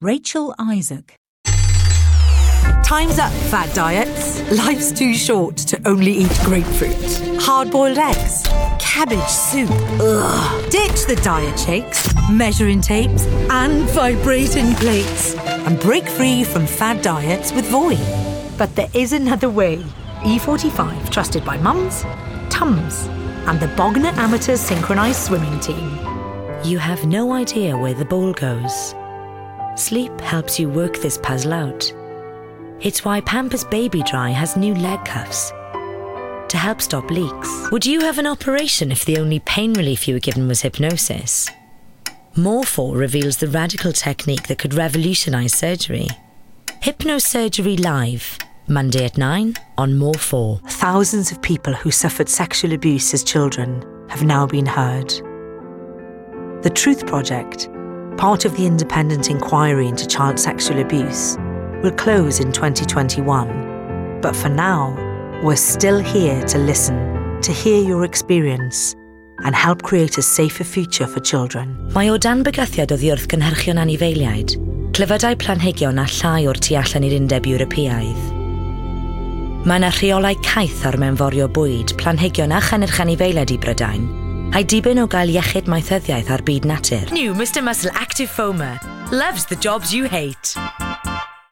Description: Welsh: warm, confident, punchy
Age range: 30s - 40s
Commercial 0:00 / 0:00
RP*, Welsh*